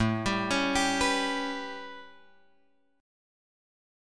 A9 chord {5 x 5 6 8 7} chord